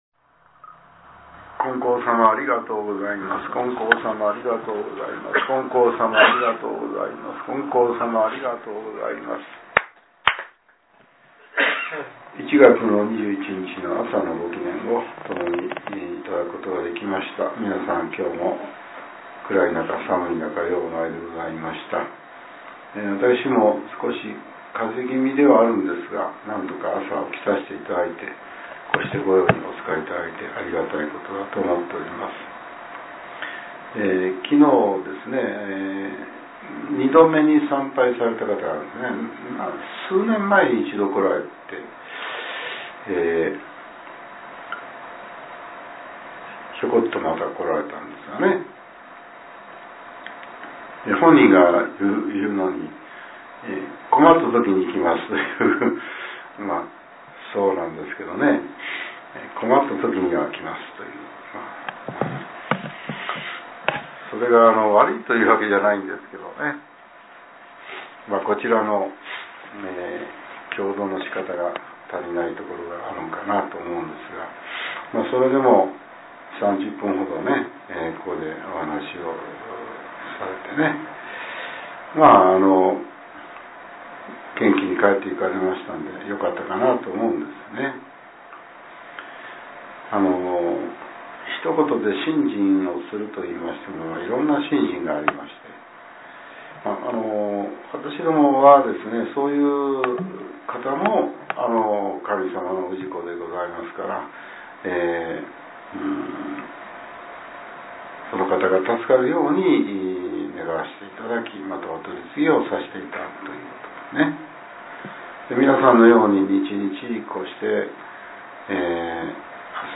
令和７年１月２１日（朝）のお話が、音声ブログとして更新されています。